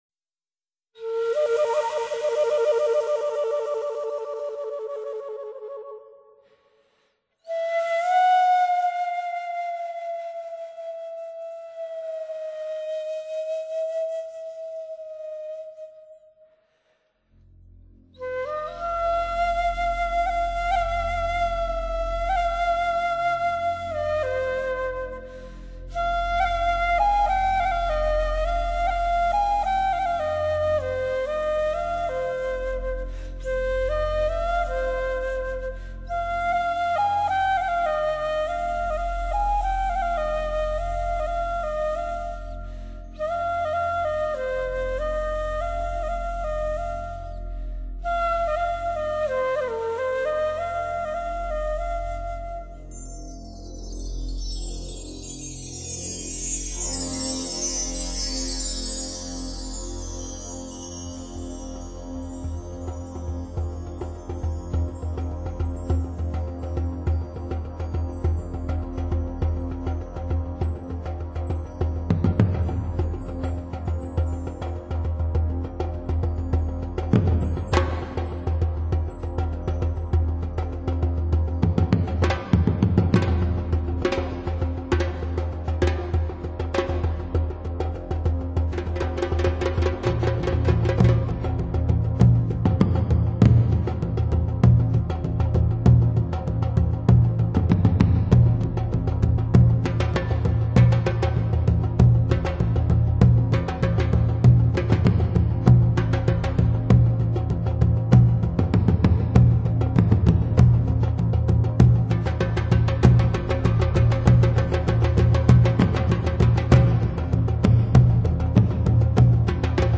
中国竹笛、键盘
西非鼓、打击乐